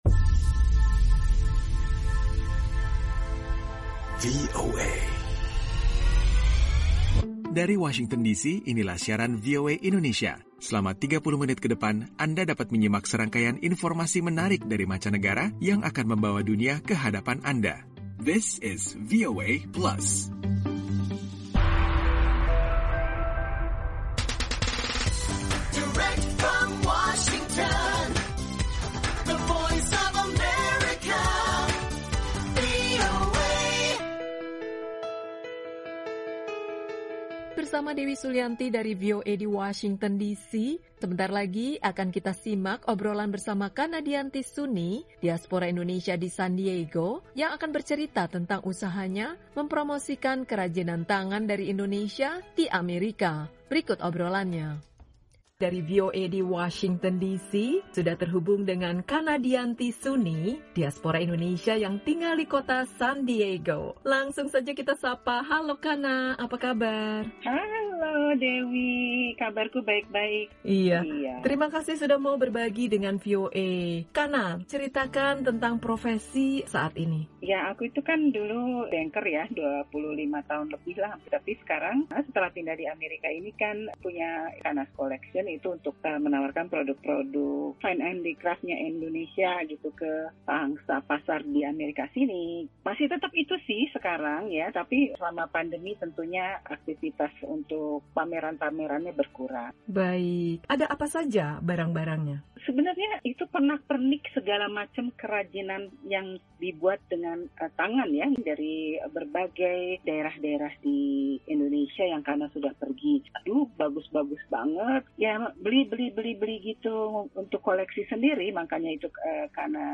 Dalam VOA Plus kali ini kita dapat menyimak perbincangan dengan seorang diaspora Indonesia di negara bagian California yang memiliki usaha mempromosikan kerajinan tangan Indonesia.